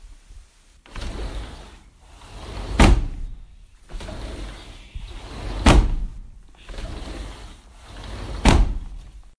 门声
描述：门开启和关闭几次的声音